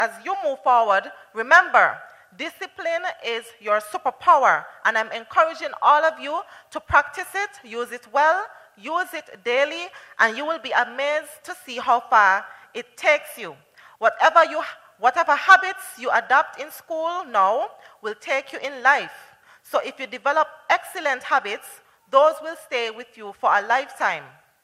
Under the theme: “United Through Discipline, United to Win”, the St. Thomas’ Primary School held its 2025 Graduation Ceremony, on Tuesday, July 1st 2025, at the Nevis Performing Arts Center (NEPAC).